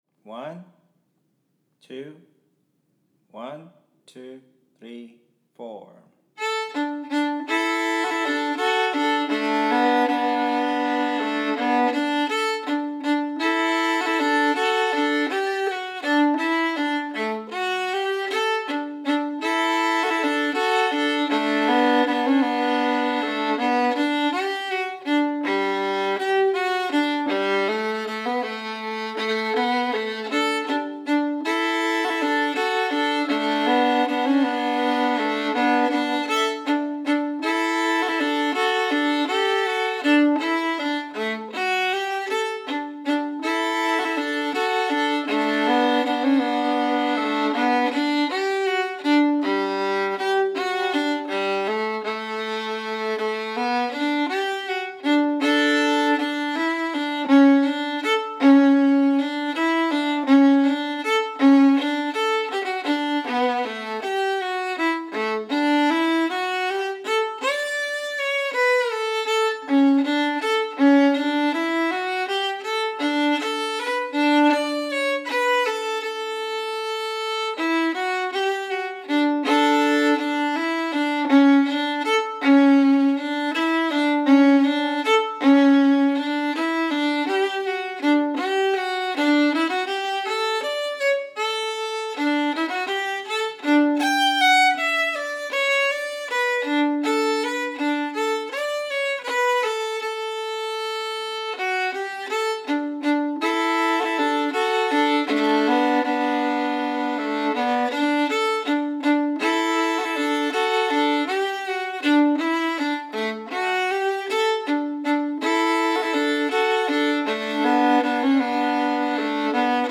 Stereo, 24bit/48khz